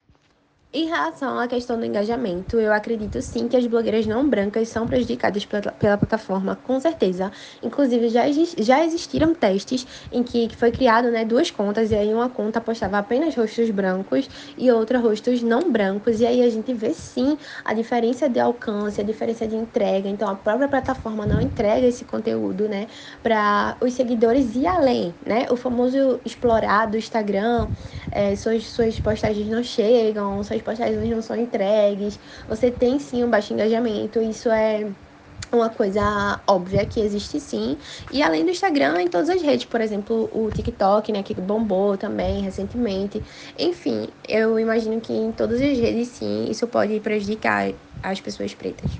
Audio-1-Entrevista-com-blogueira.mp3